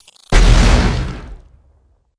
Grenade9.wav